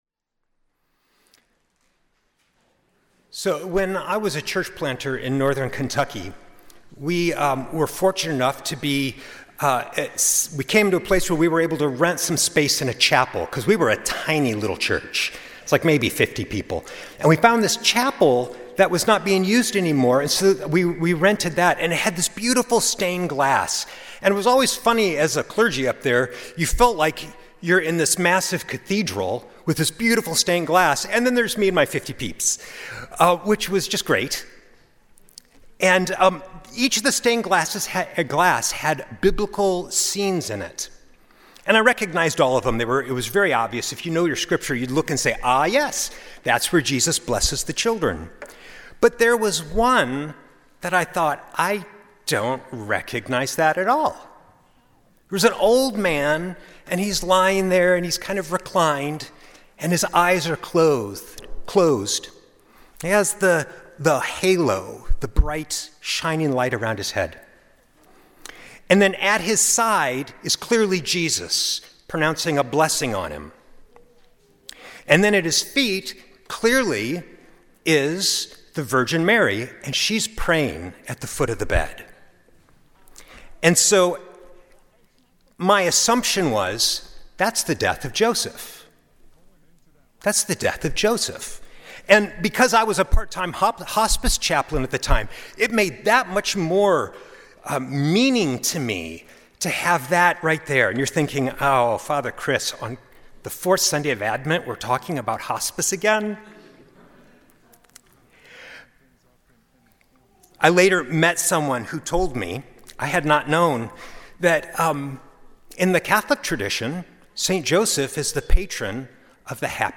Sunday Worship–Dec. 21, 2025